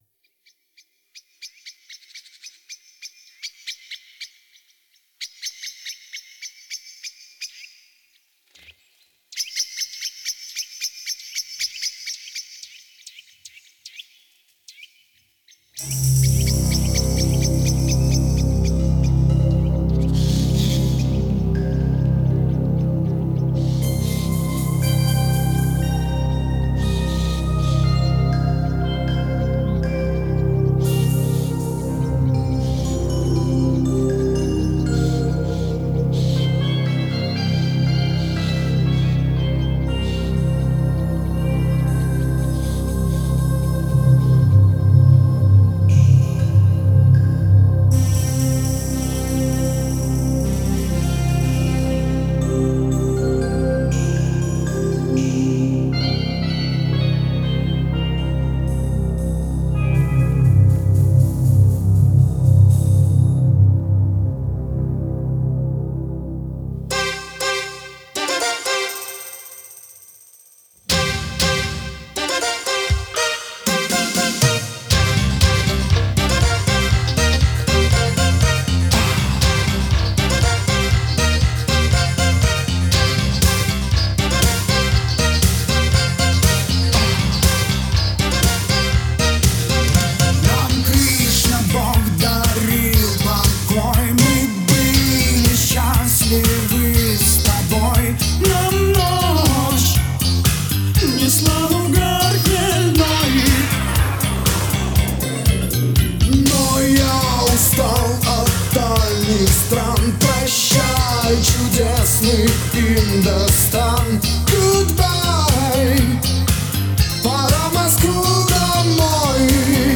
Музыкальный жанр: поп, ретро (диско 80-х)